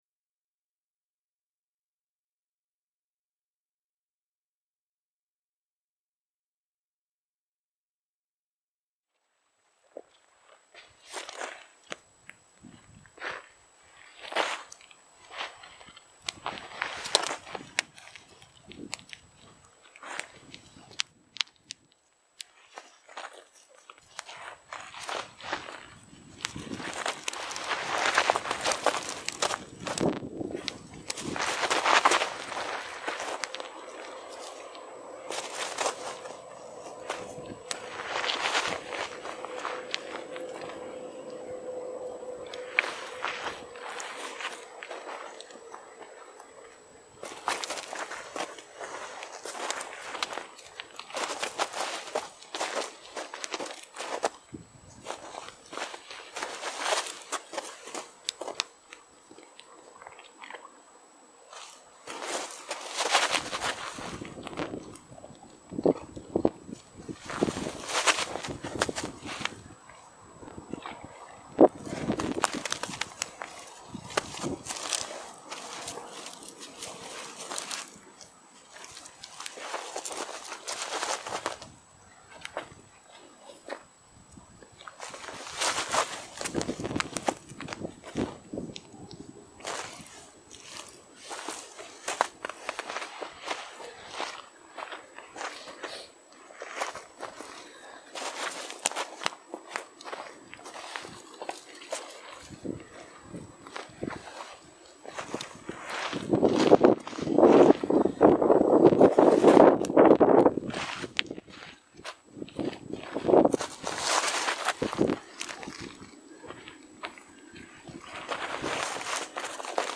several performers at different places
Play the water with the stones